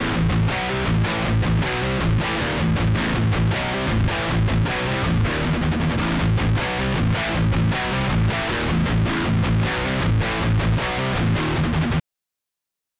3. Подматченная Euphoria с соответствующими настройками структуры и preEQ
Euph (matched).mp3